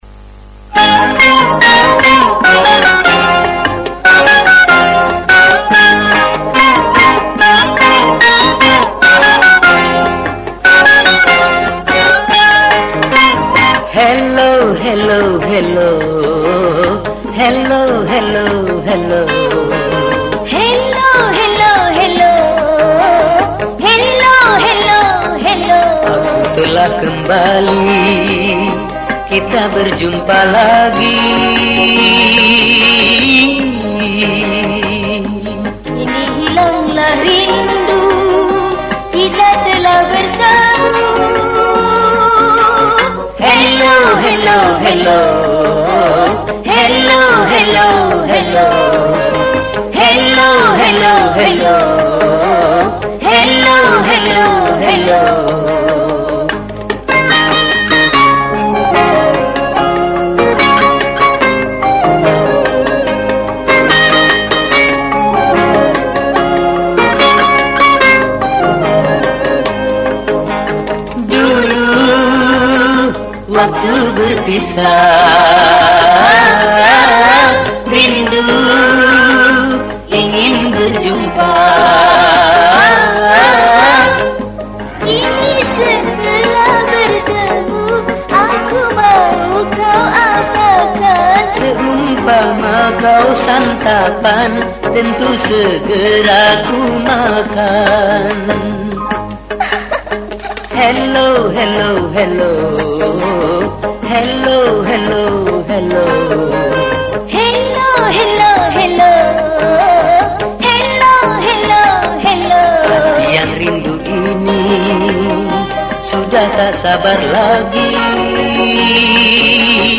Genre Musik                        : Dangdut